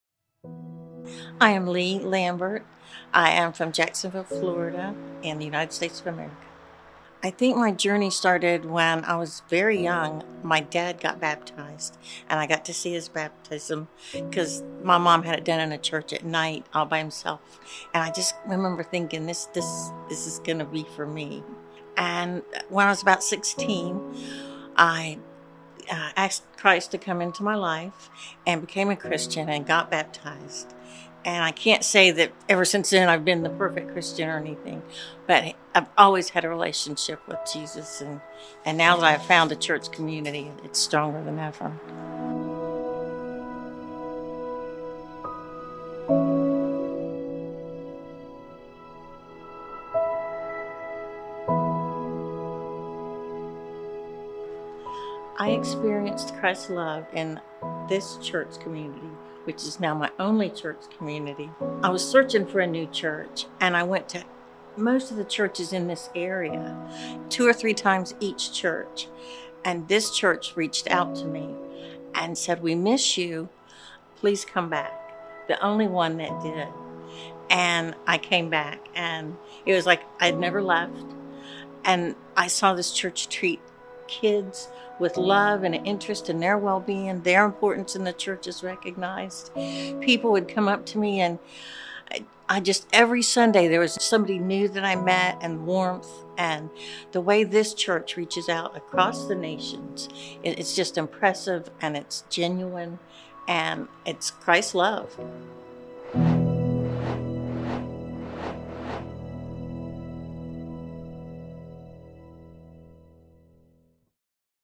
Join us as members from our global fellowship share testimonies of their experiences with Jesus through GCI congregations and ministries.